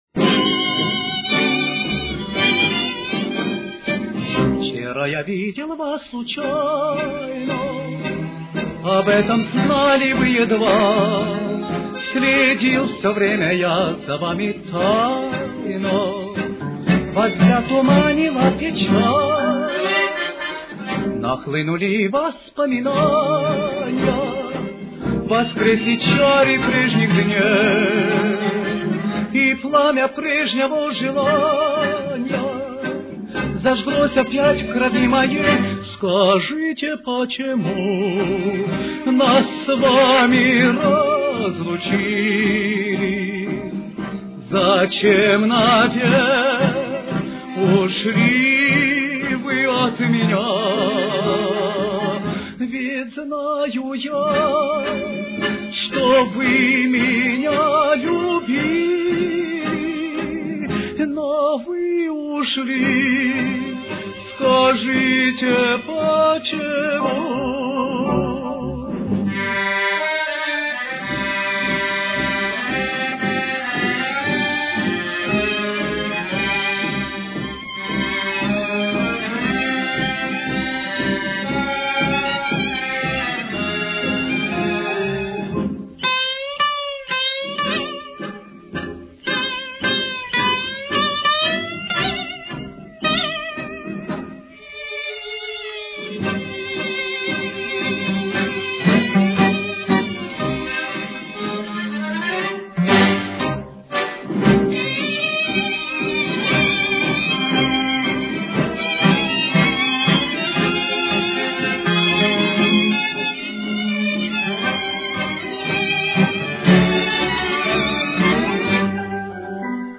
Темп: 118.